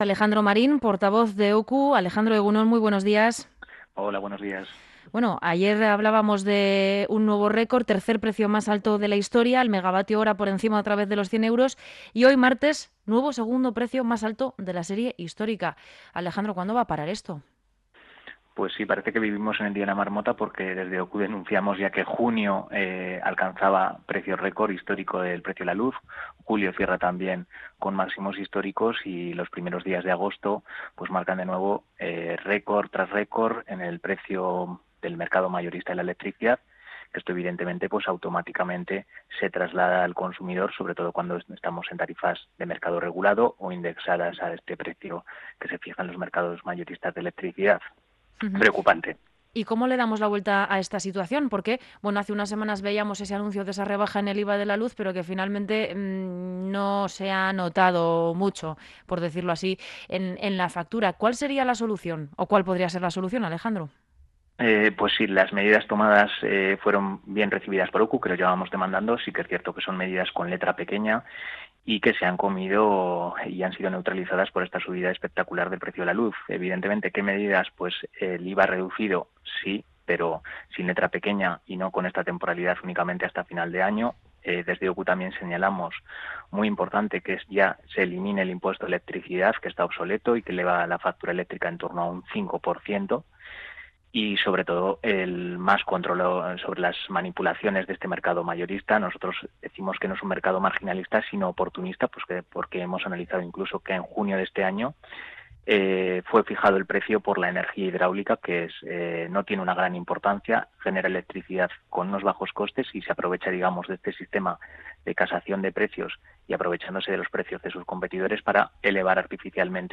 Morning show conectado a la calle y omnipresente en la red.
Entrevista